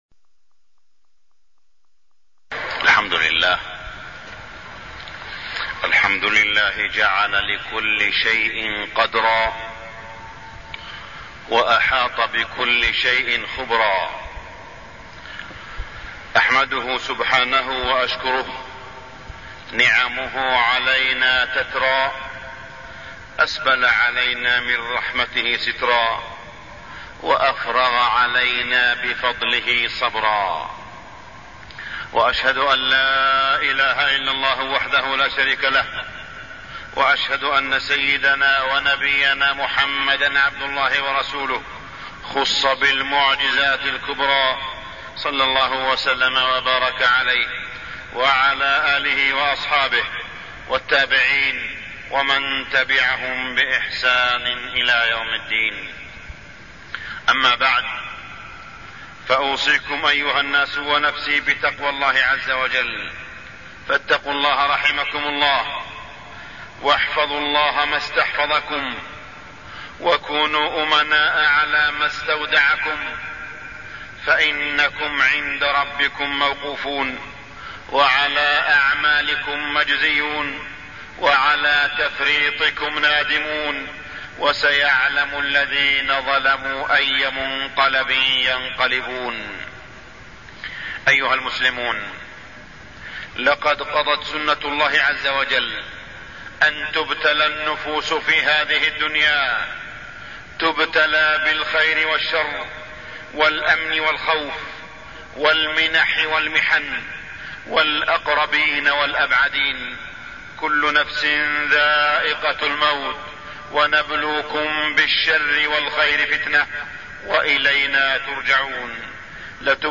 تاريخ النشر ١٦ جمادى الأولى ١٤٢٠ هـ المكان: المسجد الحرام الشيخ: معالي الشيخ أ.د. صالح بن عبدالله بن حميد معالي الشيخ أ.د. صالح بن عبدالله بن حميد الابتلاء والزلازل والمحن